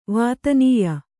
♪ vātanīya